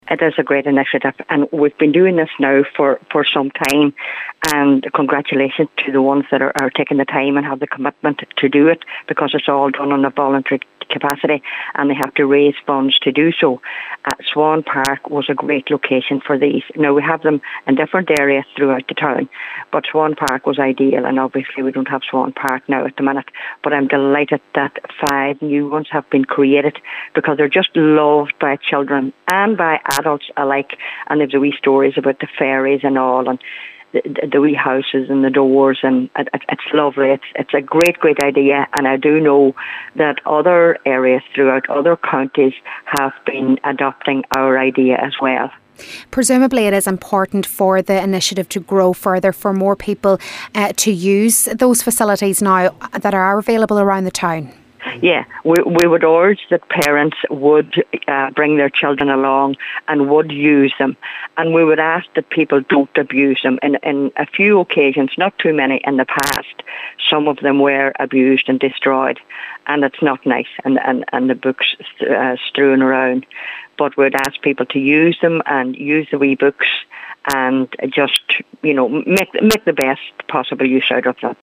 Cllr Rena Donaghy says it’s an important initiative which continues to grow: